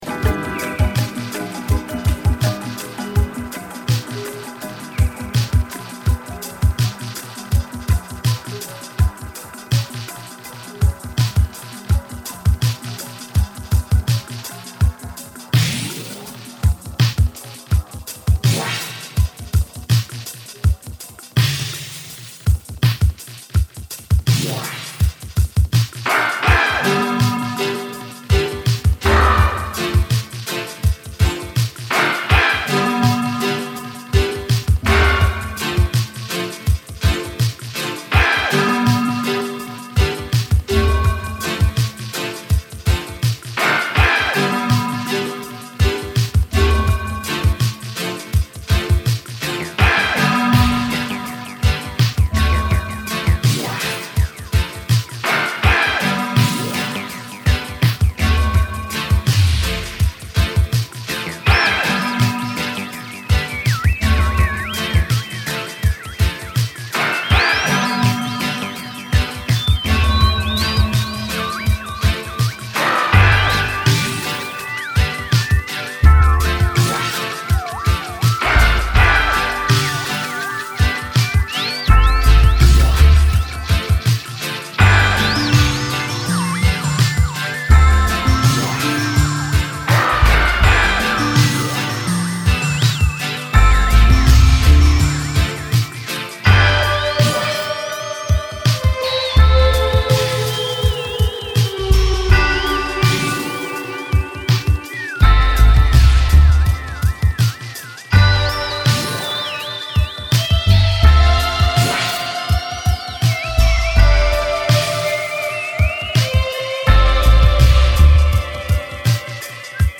新作エキゾ・アンビエント・ダブMIXミックスCD